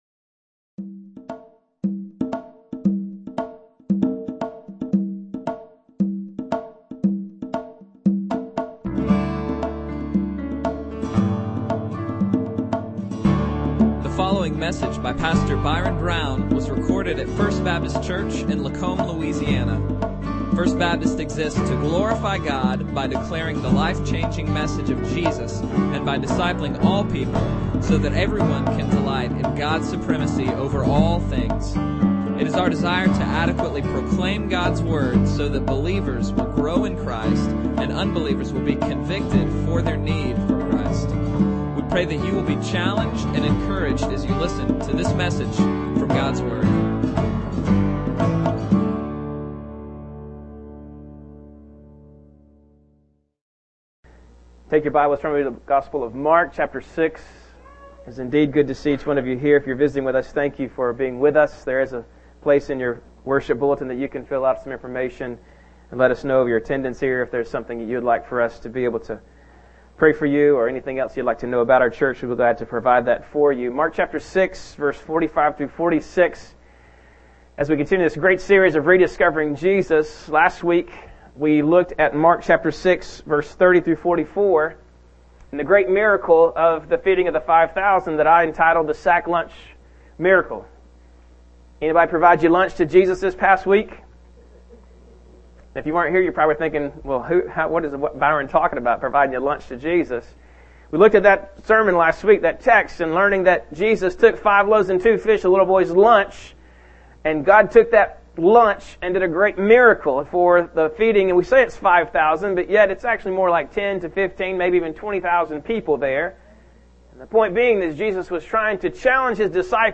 Bible Text: Mark 6:45-56 | Preacher